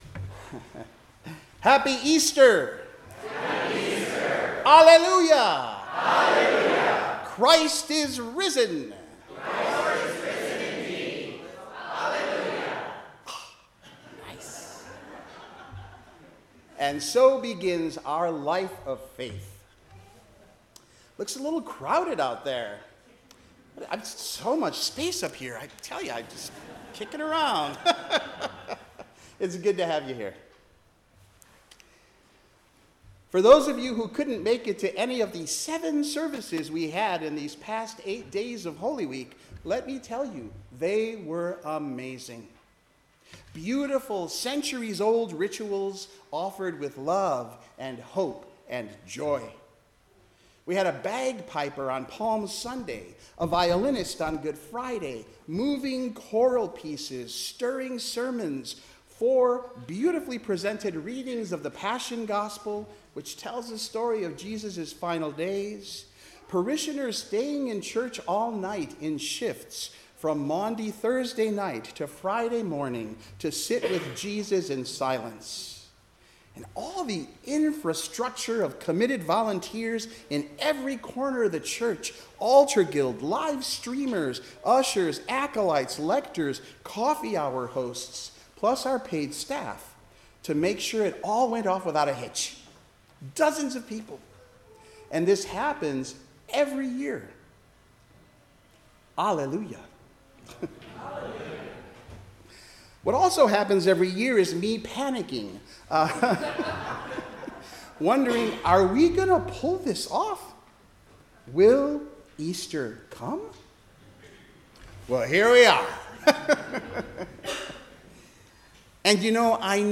Sermon-Easter-Day-April-5-2026.mp3